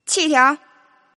Index of /client/common_mahjong_tianjin/mahjonghntj/update/1308/res/sfx/tianjin/woman/